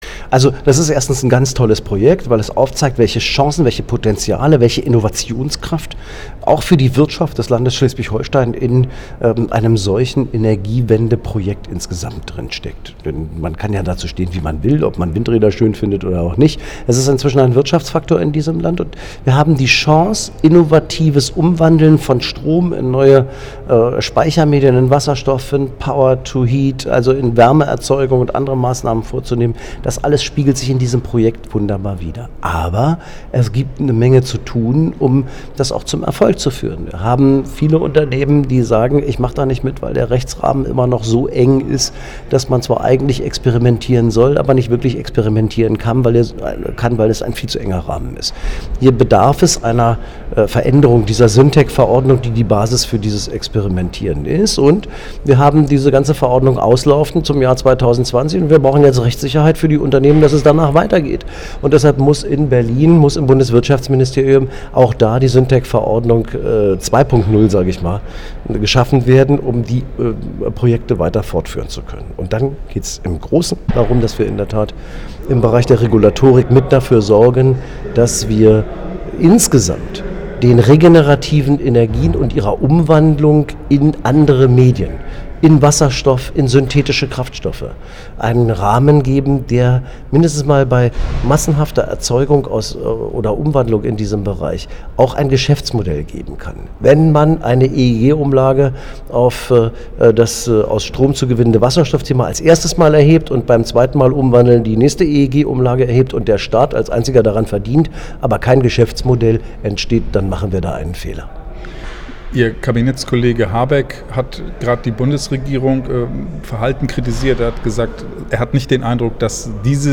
NEW 4.0 Konsortialtreffen in KielWirtschaftsminister Bernd Buchholz sagte zu dem Projekt und dem heutigen Treffen